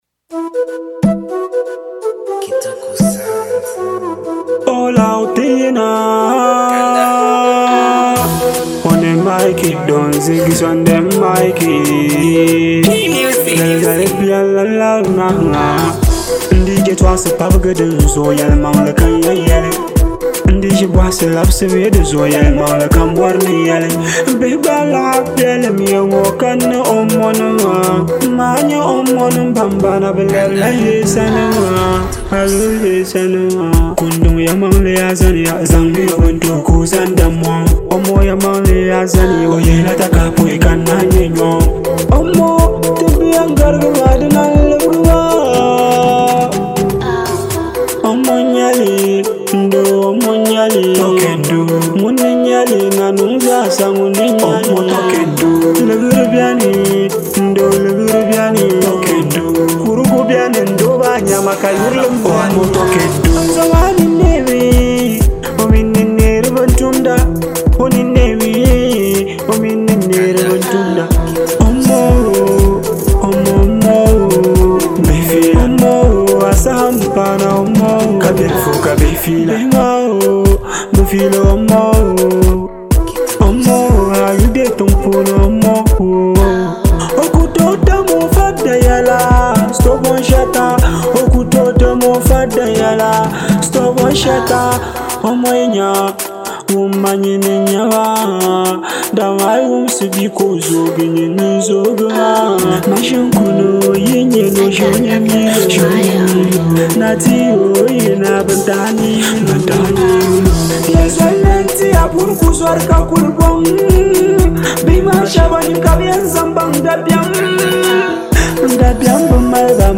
With its strong message and infectious vibe